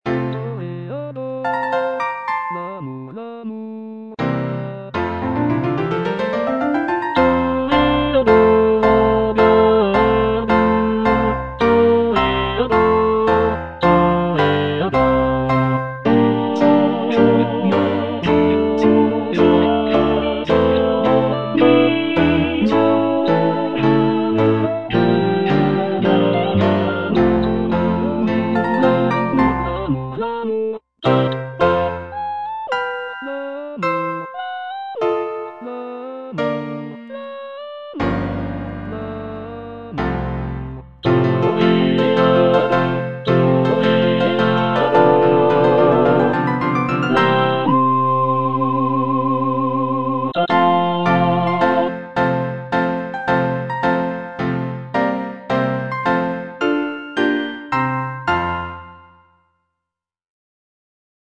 G. BIZET - CHOIRS FROM "CARMEN" Toreador song (II) (All voices) Ads stop: auto-stop
The music captures the essence of Spanish culture with its passionate melodies and rhythmic flair.